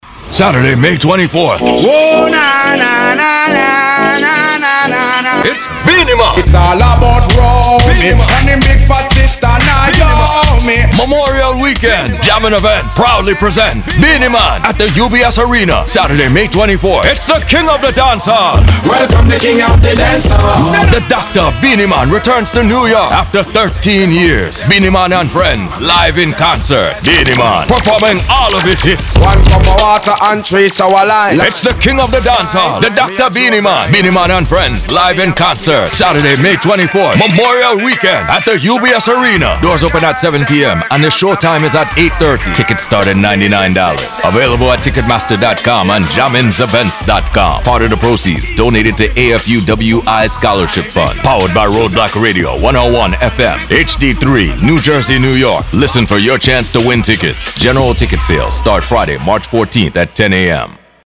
Authentic Jamaican and Caribbean Voiceover